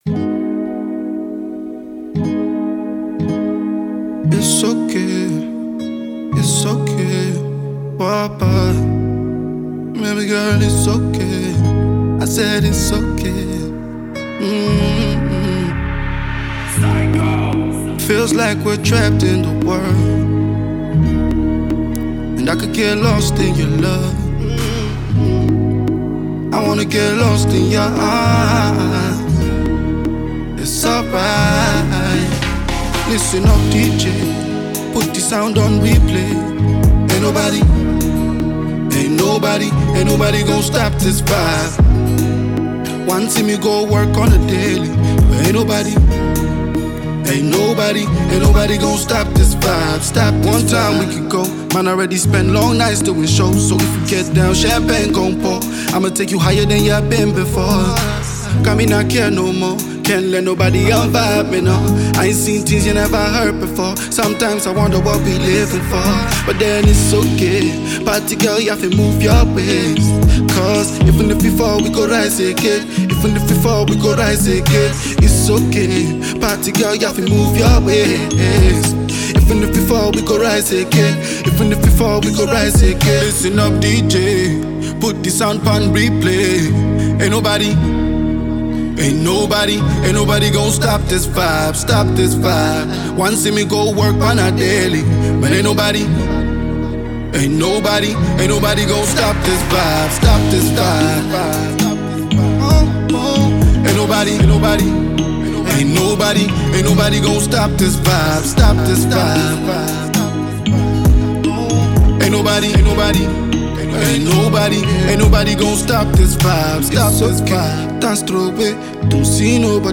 hot up-tempo record